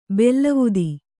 ♪ bellavudi